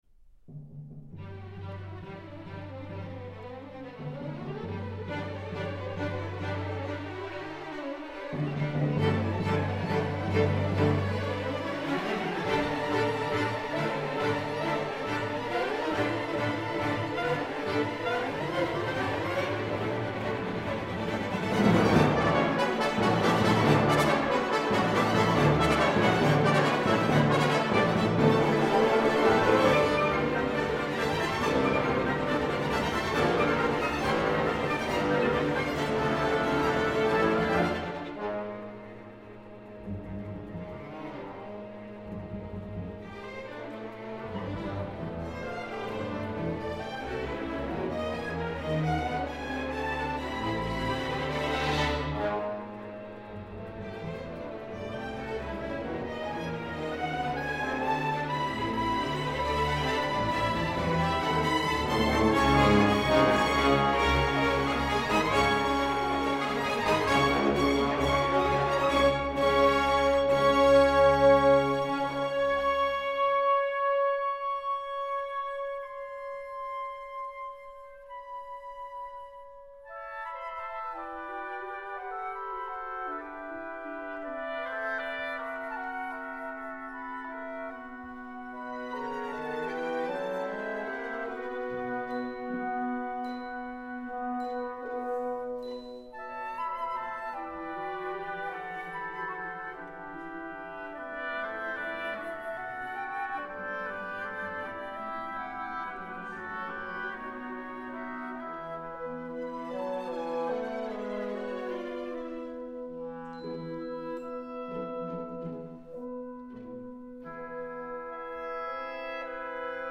Period: 20th Century